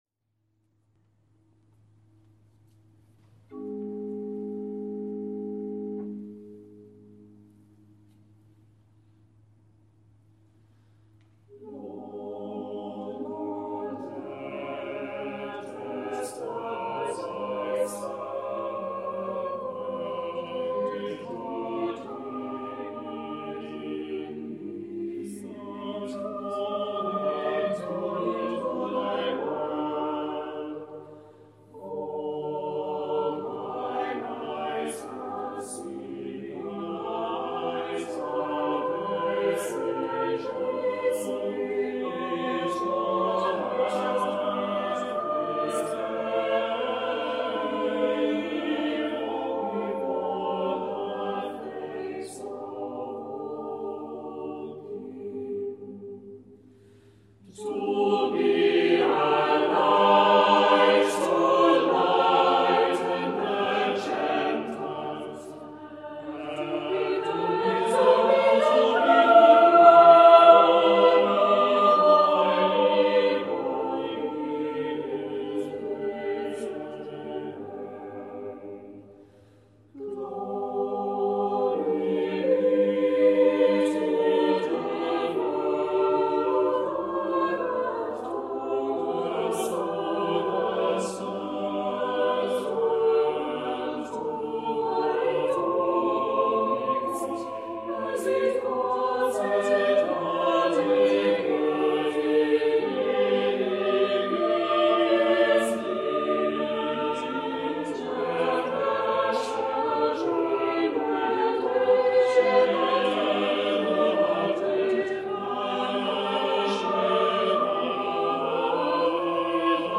Nunc Dimittis from Gibbons Short Service, sung by the Priory Singers of Belfast at Truro Cathedral